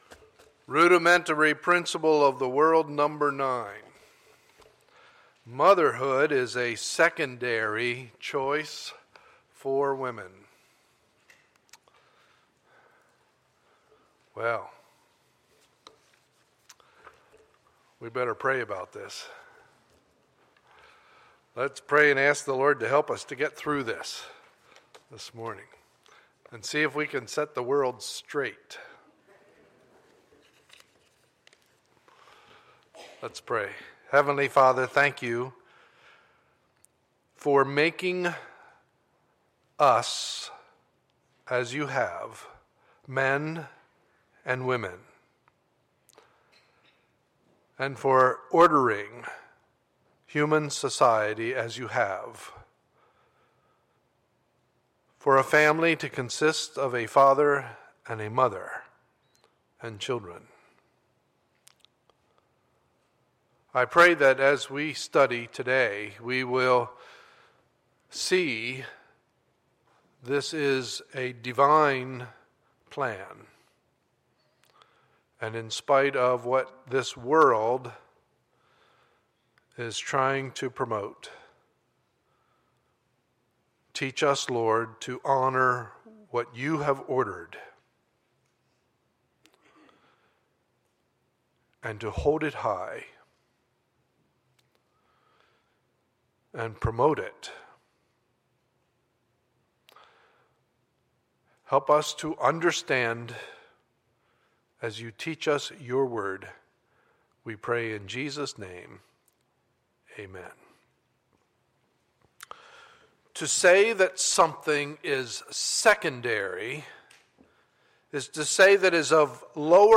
Sunday, May 11, 2014 – Morning Service